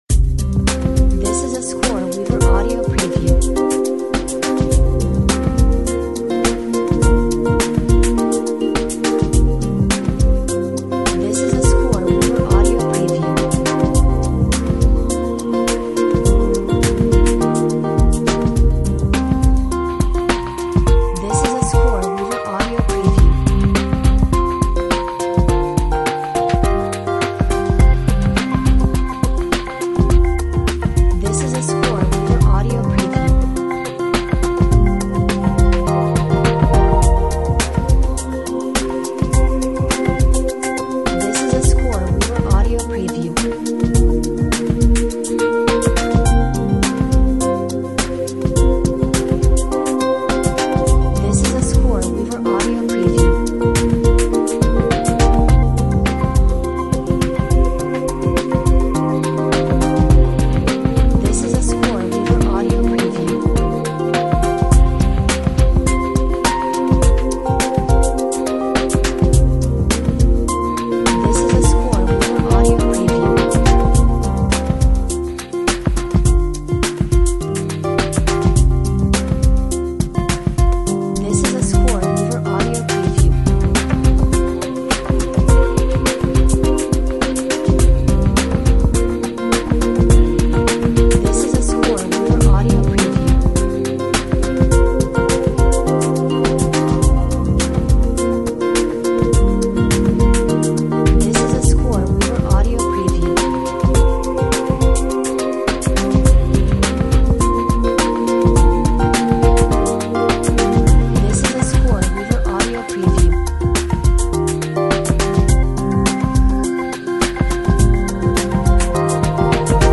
Smooth lounge electronica with a lovely guitar melody.